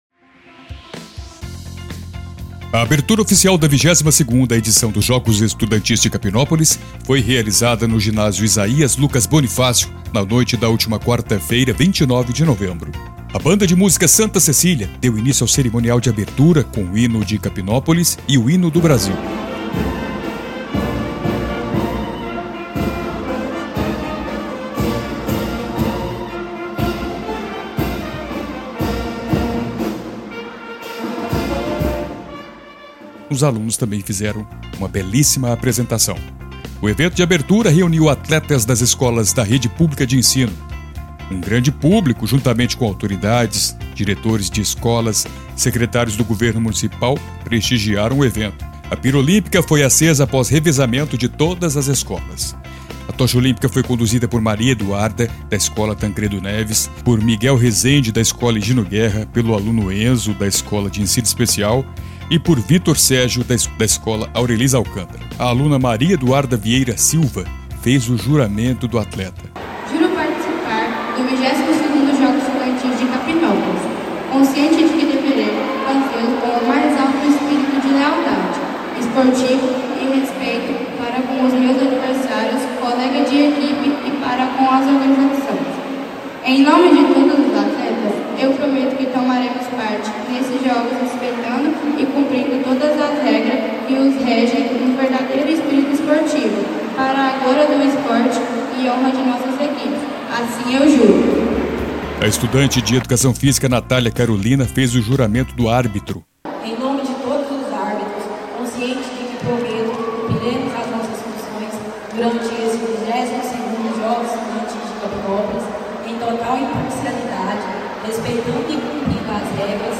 Capinópolis, Minas Gerais. A abertura oficial da 22ª edição dos Jogos Estudantis de Capinópolis foi realizada no ‘Ginásio Isaías Lucas Bonifácio’ na noite desta quarta-feira (29.Nov.23).